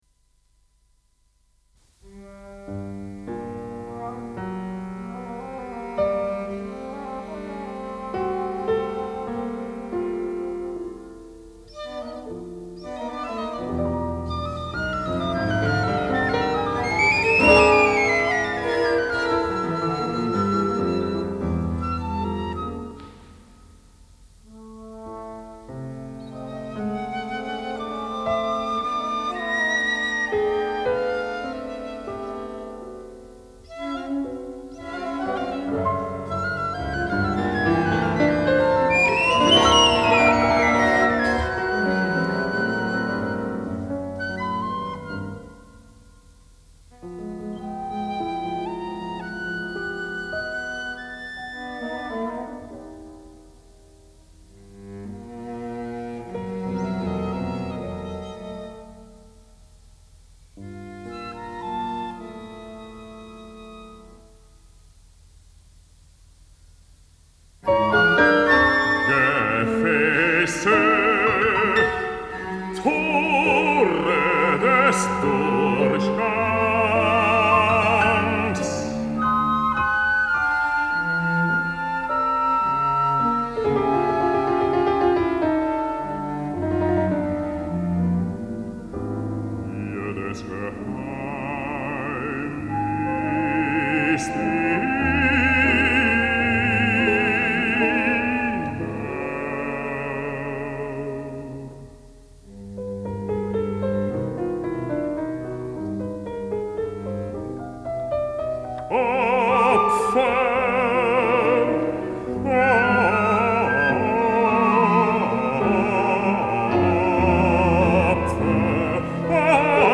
Gesang mit Kammerensemble
für Bariton, zwei Flöten, Violoncello und Klavier (1977) 14’